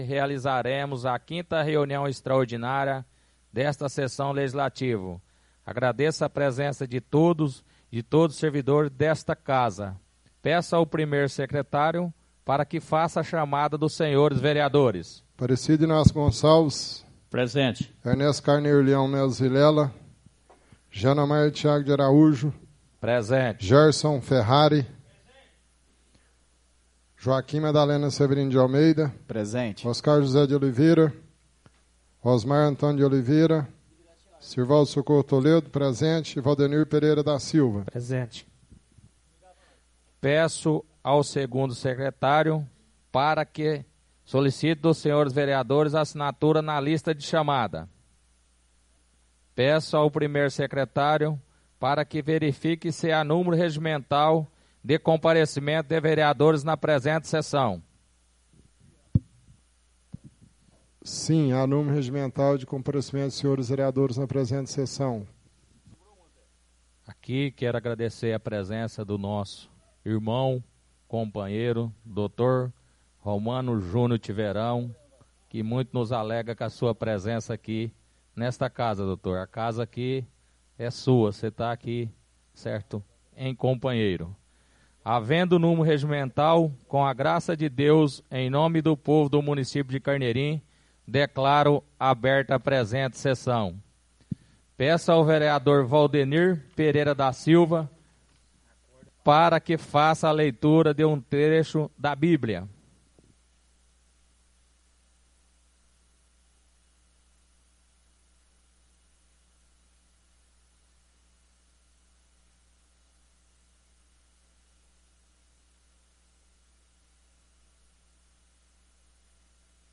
Áudio da 5ª sessão extraordinária de 2016, realizada no dia 14 de Julho de 2016, na sala de sessões da Câmara Municipal de Carneirinho, Estado de Minas Gerais.